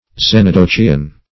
Xenodochium \Xen`o*do*chi"um\ (z[e^]n`[-o]*d[-o]*k[imac]"[u^]m),